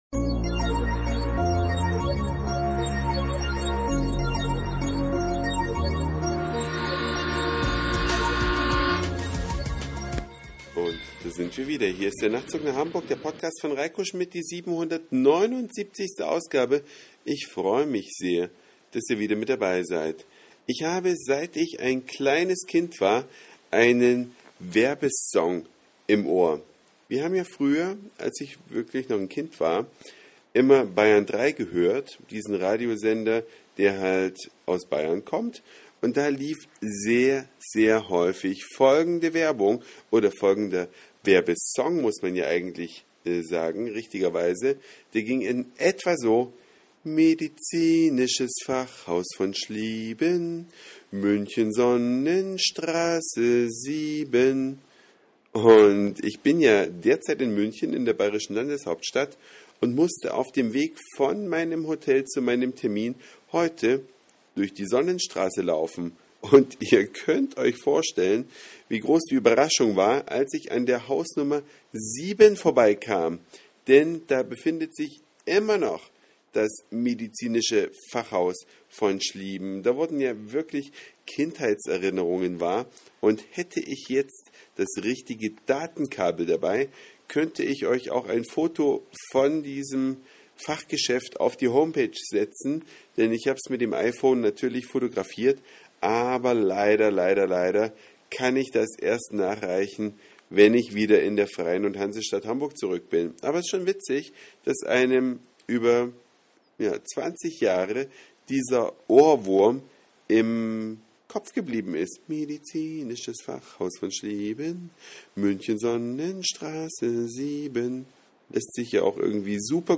Eine Reise durch die Vielfalt aus Satire, Informationen, Soundseeing und Audioblog.
Ein Werbesong und die Realität in der Sonnenstr. 7.